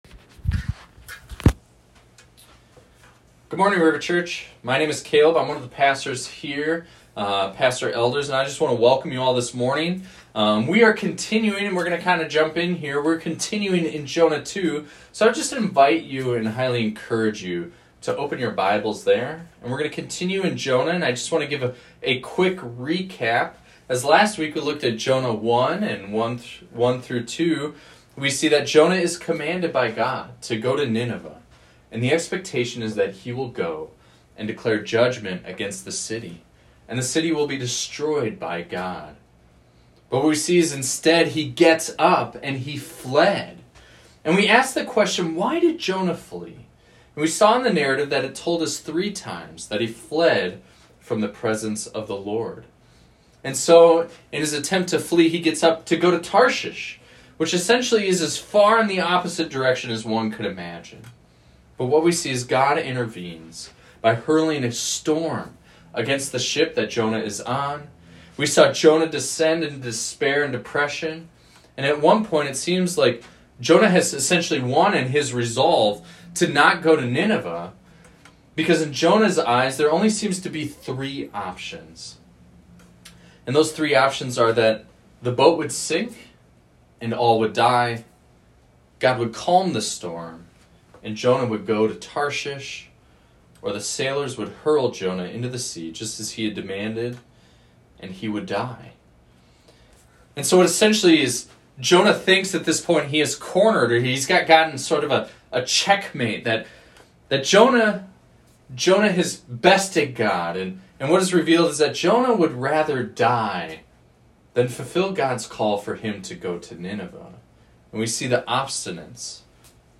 This is a recording of a sermon titled, "Chapter 2."